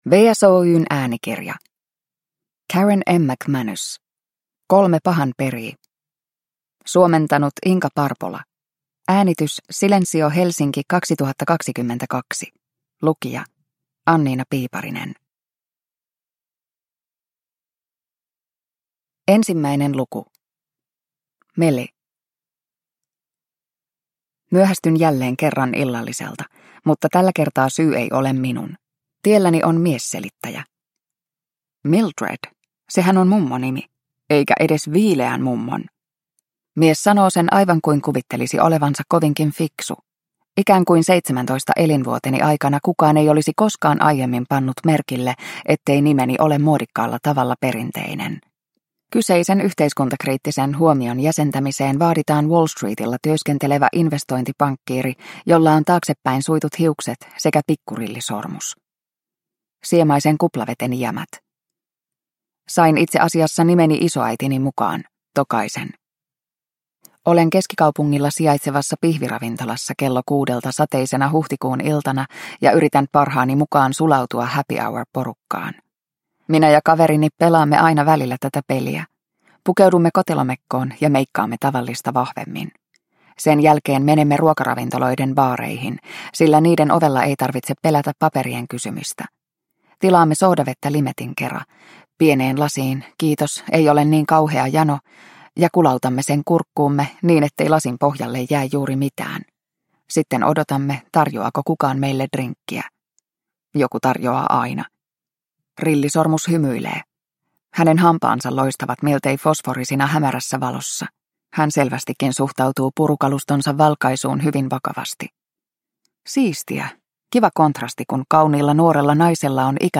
Kolme pahan perii – Ljudbok – Laddas ner